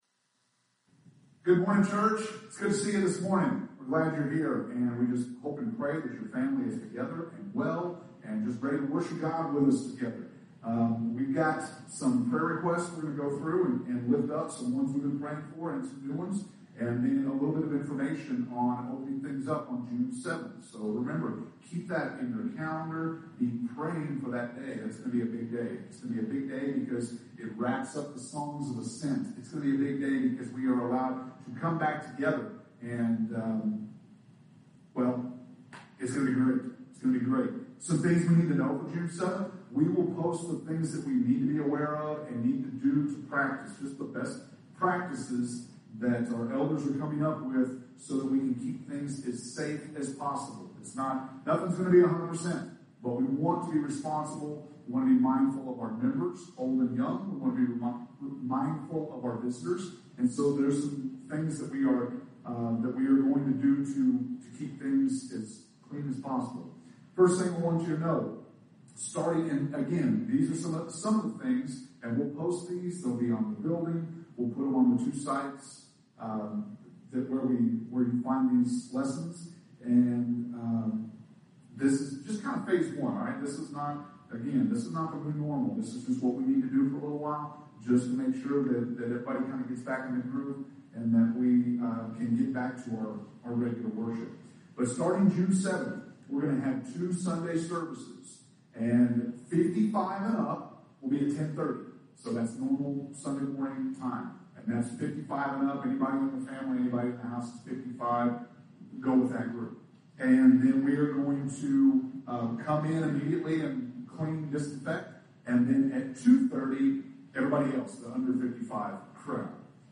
May 24th – Sermons